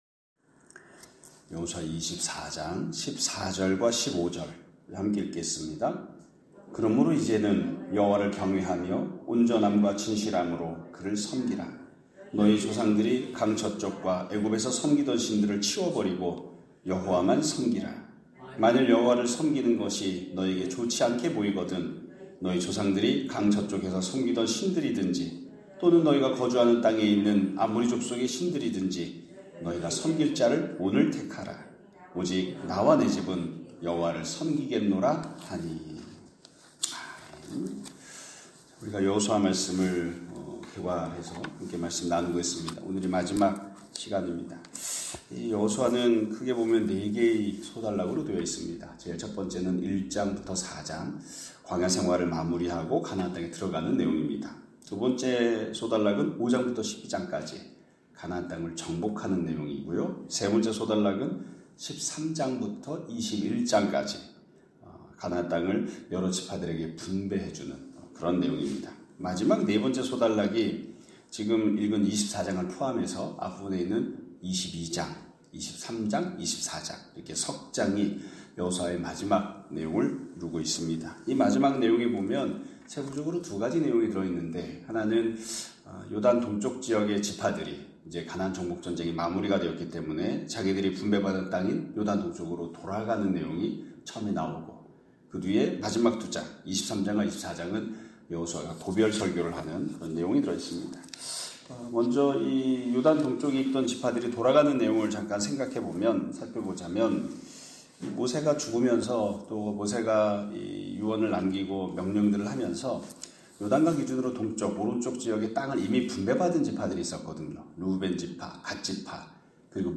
2025년 3월 17일(월요일) <아침예배> 설교입니다.